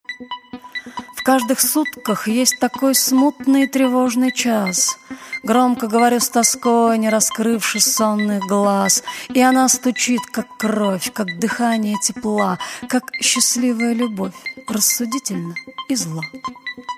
3. «Анна Ахматова – В каждых сутках есть такой.. (читает С. Сурганова)» /